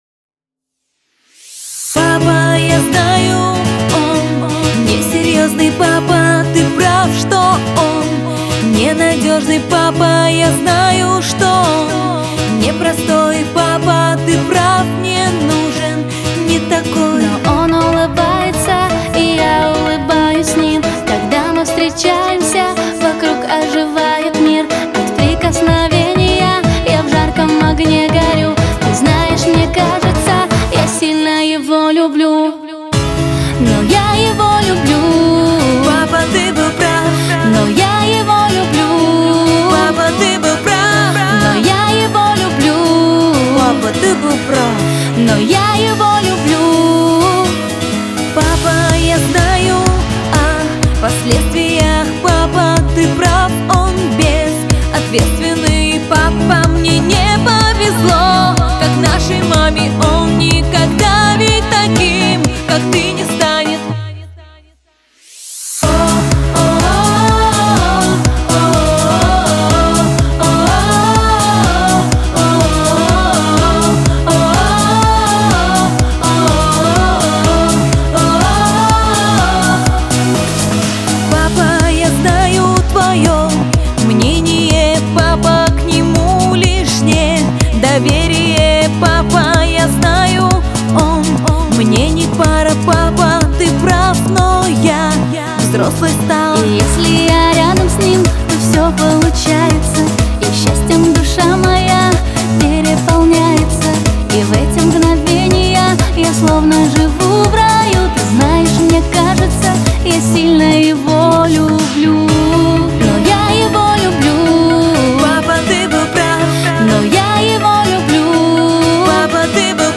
Категория: Поп